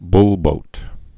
(blbōt)